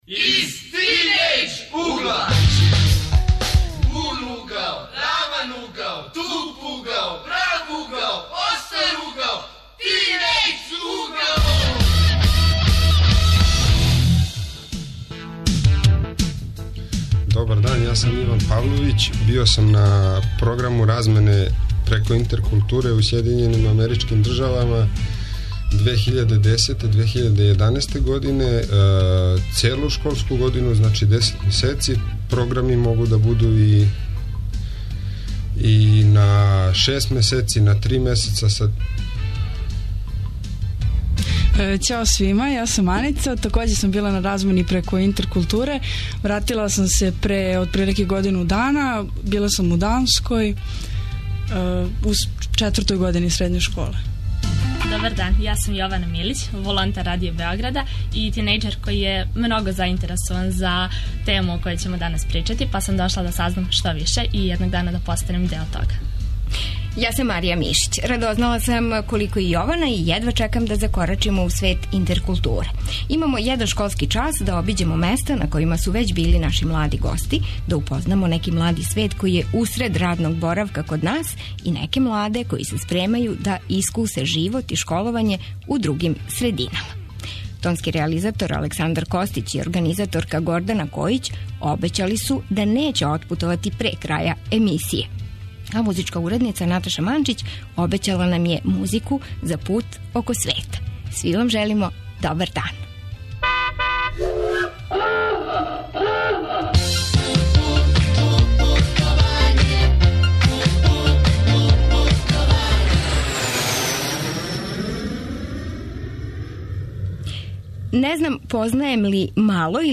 Тинејџери ове суботе ћаскају о ђачкој и студентској размени углавном на српском, иакосу им матерњи уз српски италијански,шпански, руски.